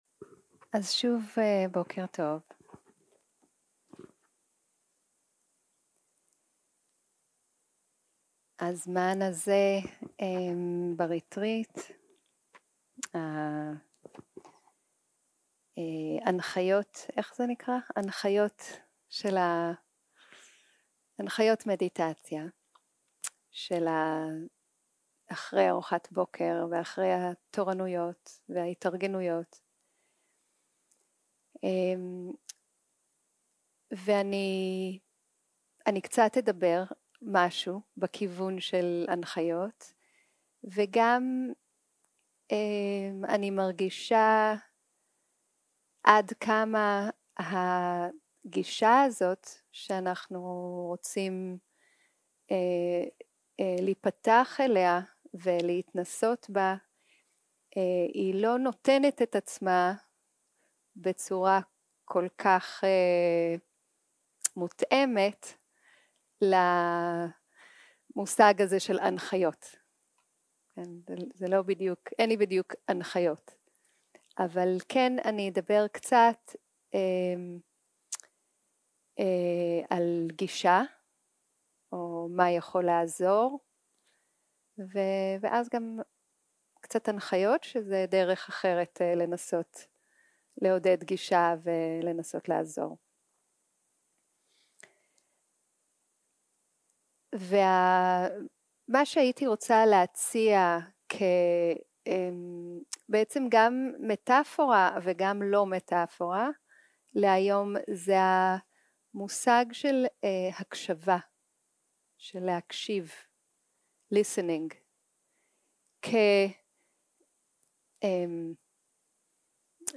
יום 2 - הקלטה 2 - בוקר - הנחיות למדיטציה
סוג ההקלטה: שיחת הנחיות למדיטציה